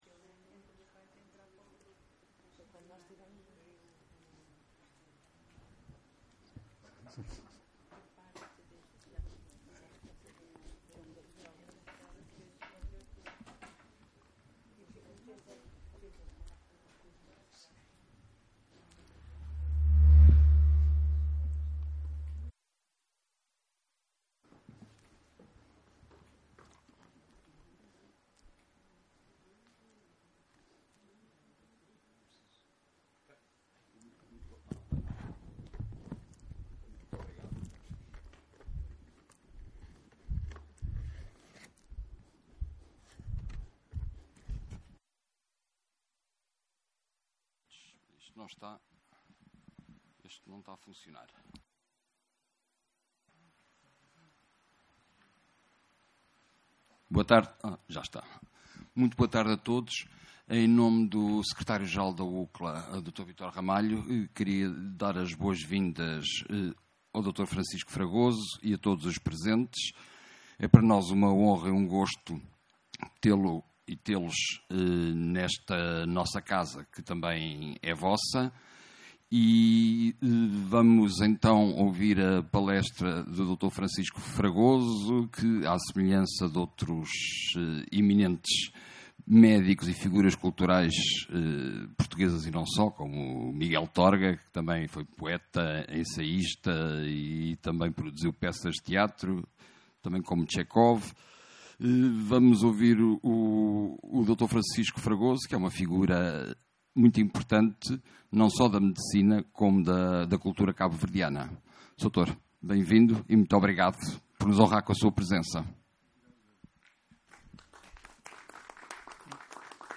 Ouça aqui a palestra sobre “Medicina e Teatro”